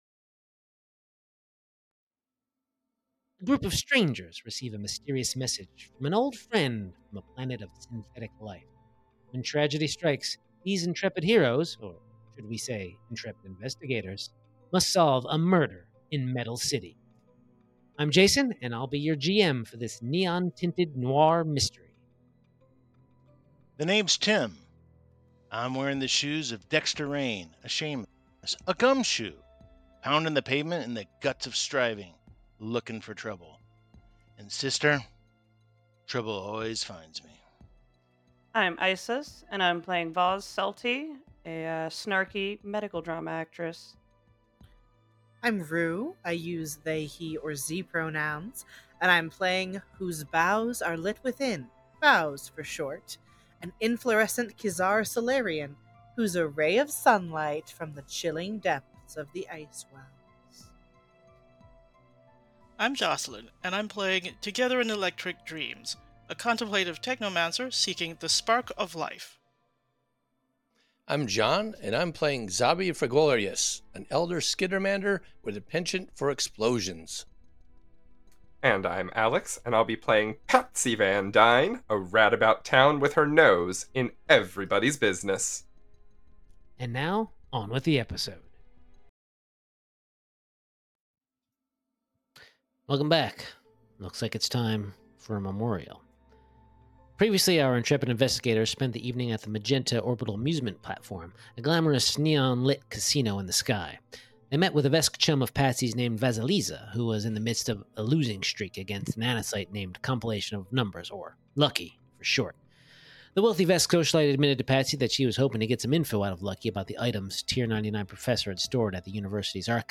Starfinder 2nd Edition Actual Play Podcast